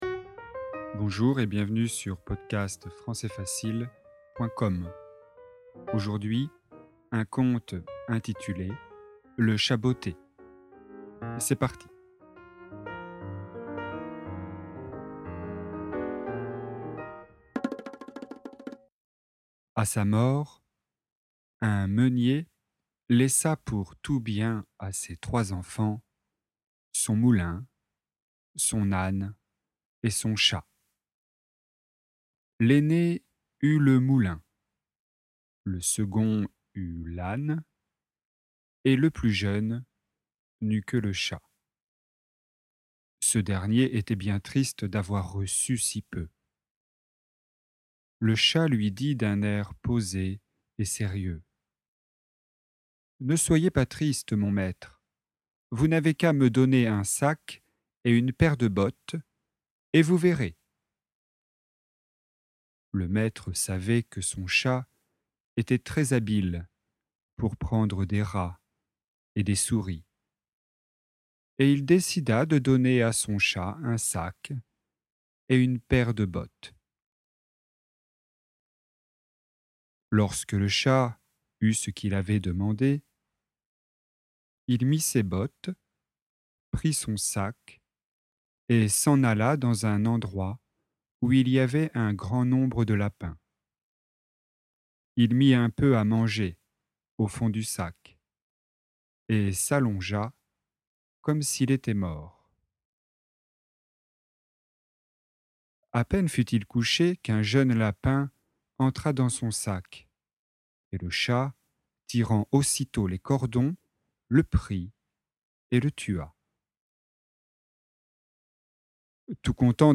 Conte (audio et texte), niveau intermédiaire (B1).